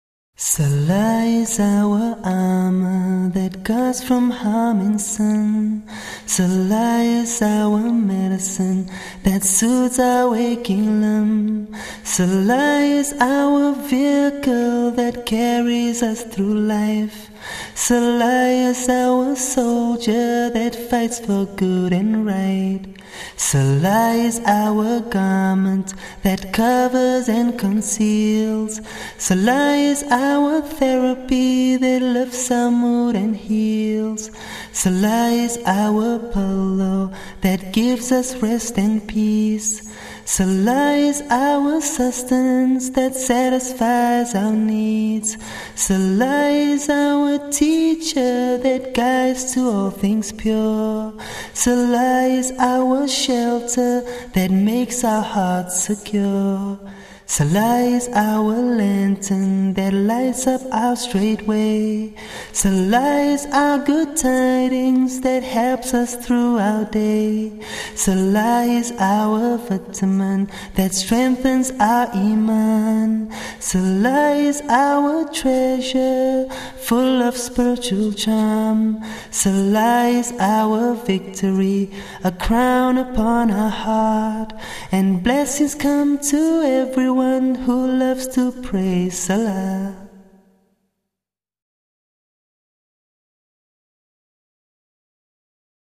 who has achieved fame as a performer of nasheed songs.